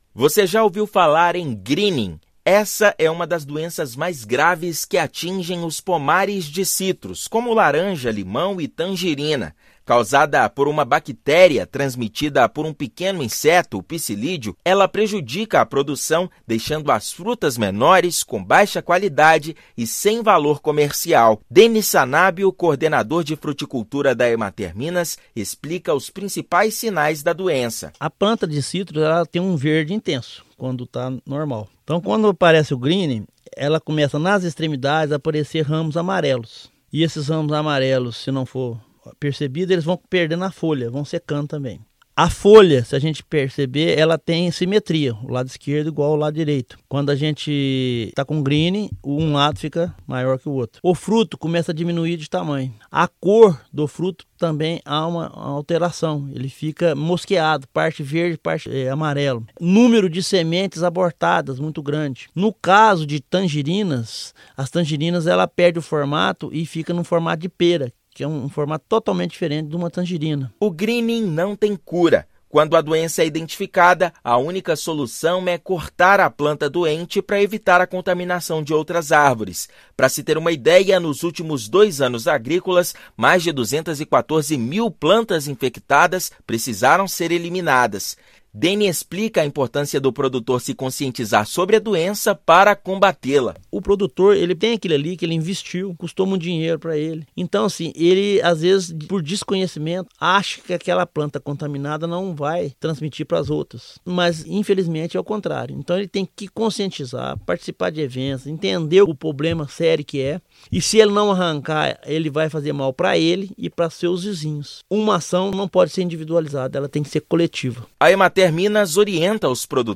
Doença que atinge a citricultura já foi responsável pela erradicação de mais de um milhão de plantas no estado. Ouça matéria de rádio.